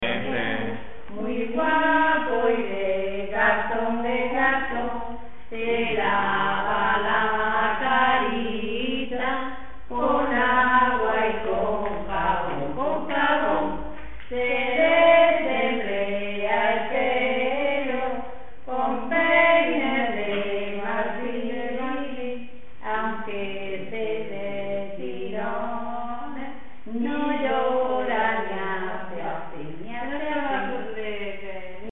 Materia / geográfico / evento: Canciones de comba Icono con lupa
Moraleda de Zafayona (Granada) Icono con lupa
Secciones - Biblioteca de Voces - Cultura oral